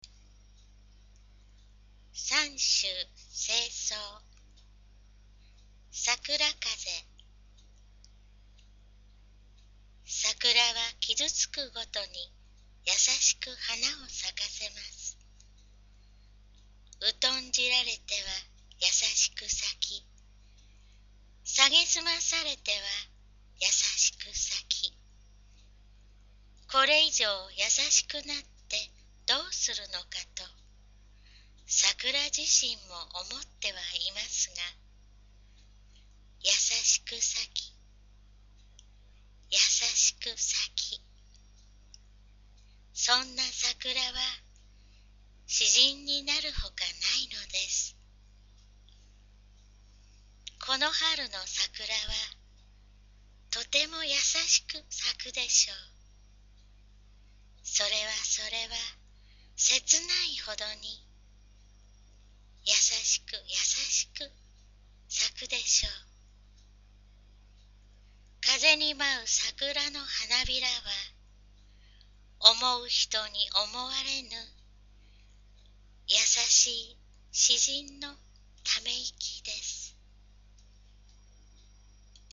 poemreadsakurakaze001.mp3